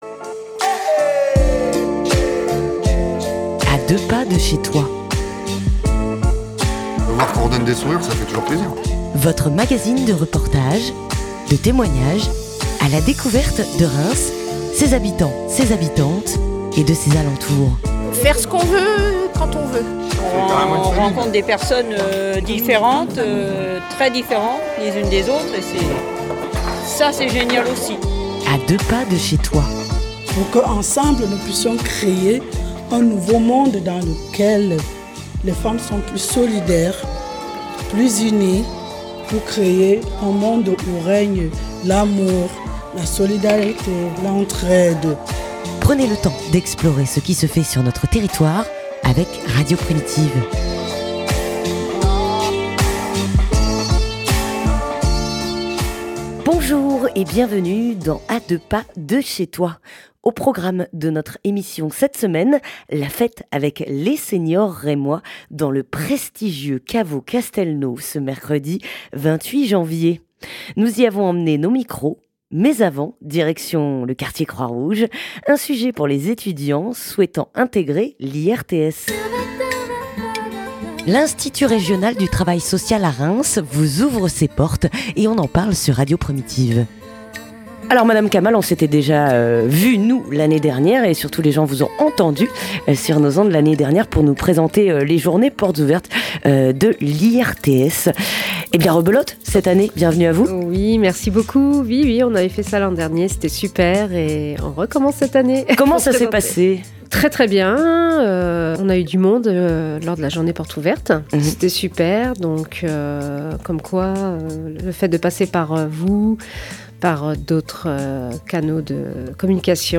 (de 00:00 à 13:00) Retour sur le bal des rois 2026 : Pendant une après-midi, la ville de Reims réserve le caveau du champagne Castelnau aux 65+ pour une après-midi dansante avec une petite coupe et la galette de rois.
C’est l’occasion pour radio primitive de leur tendre une nouvelle fois notre micro.